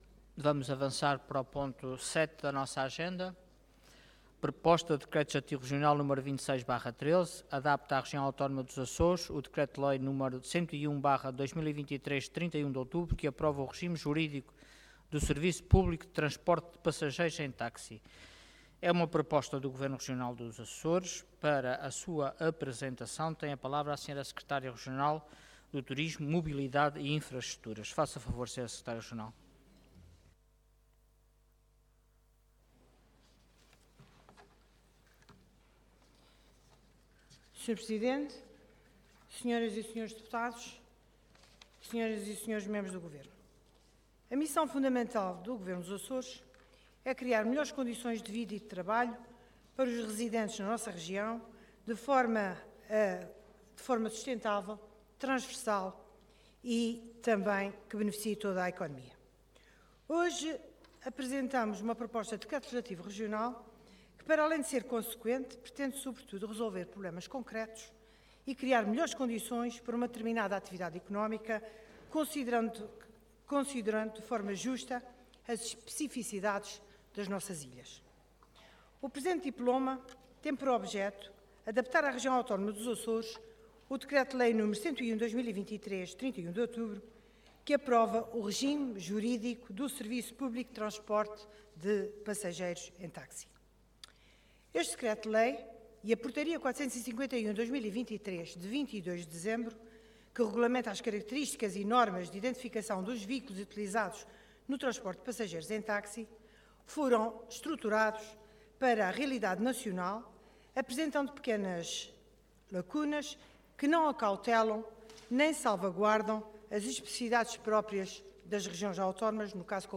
Intervenção
Orador Berta Cabral Cargo Secretária Regional do Turismo, Mobilidade e Infraestruturas Entidade Governo